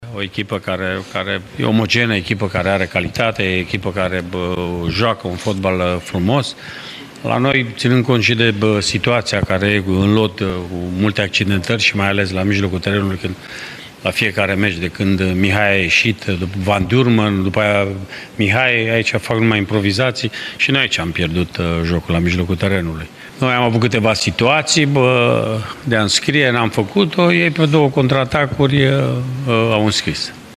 La final, în primele declarații, antrenorul Mircea Rednic a lăudat prestația gazdelor, fiind dezamăgit de cea a propriilor jucători:
Rednic-lauda-Sibiul-si-e-trist-pt-UTA.mp3